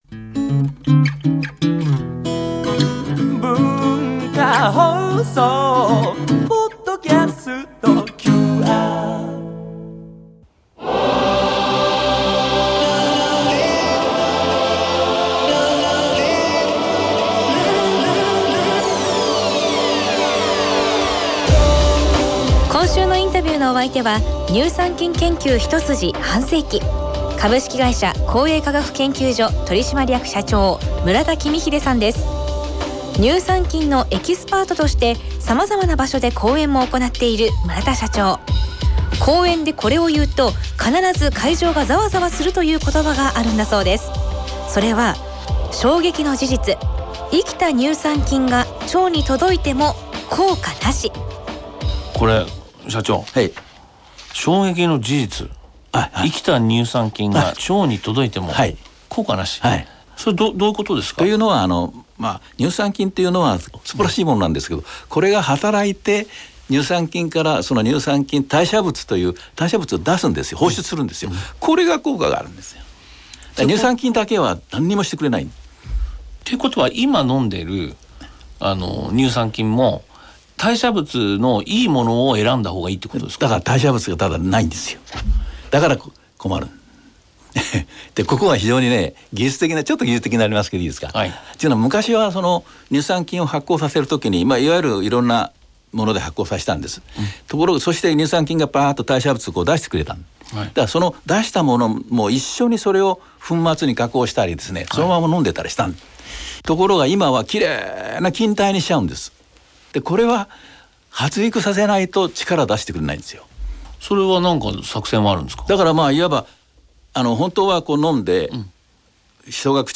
毎週、現代の日本を牽引するビジネスリーダーの方々から次世代につながる様々なエピソードを伺っているマスターズインタビュー。
（月）～（金）AM7：00～9：00　文化放送にて生放送！